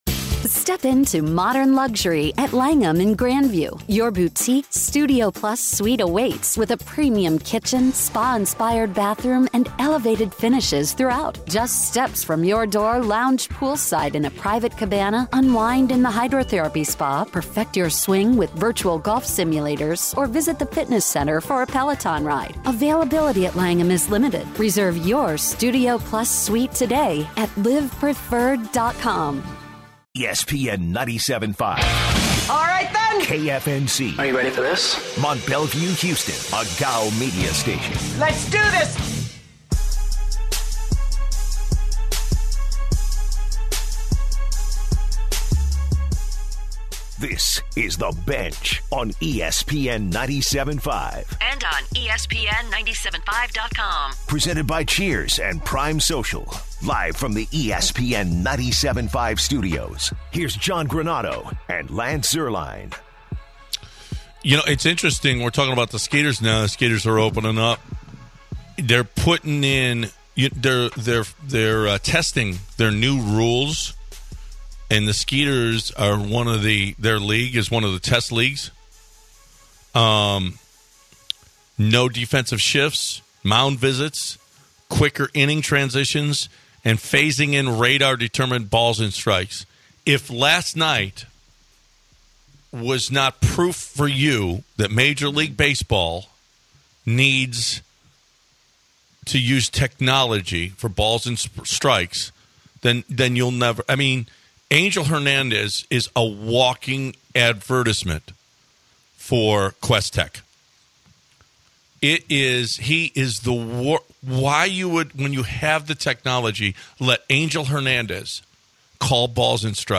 calls into the studio